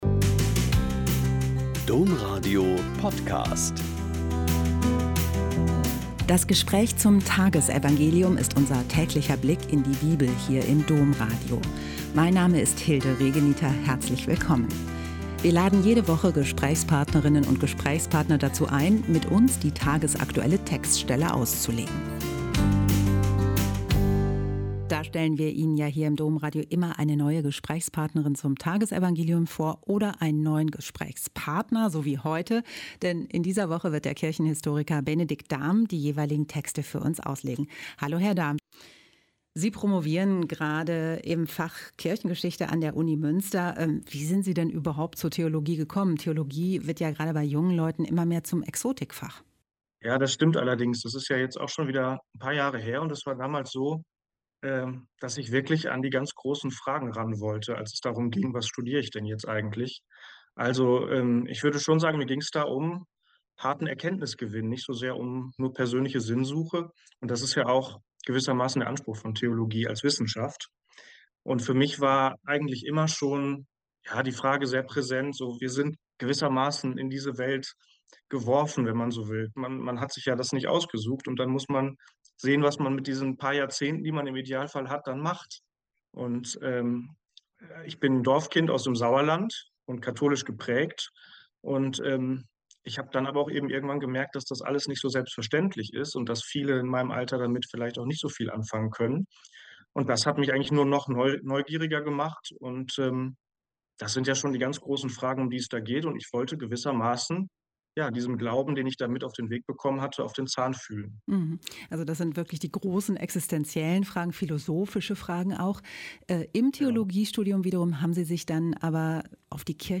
Mt 13,31-35 - Gespräch